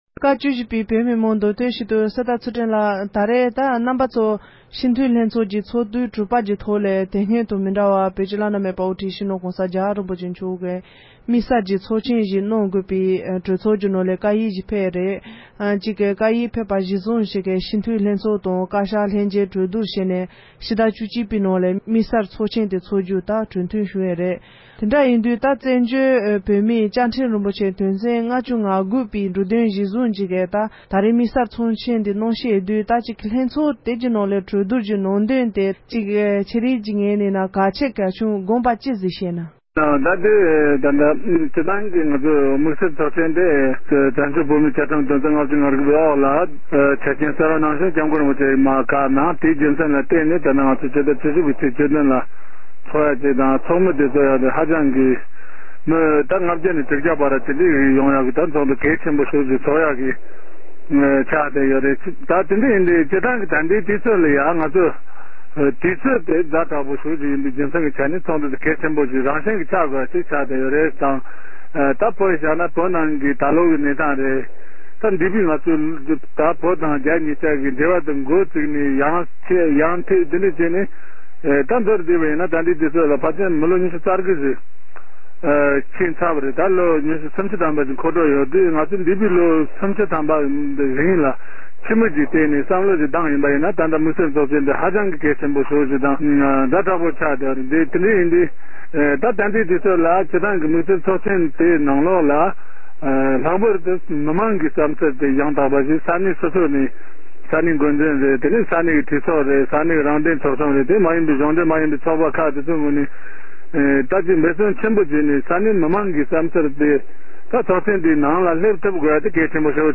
རང་དབང་གི་གླེང་མོལ།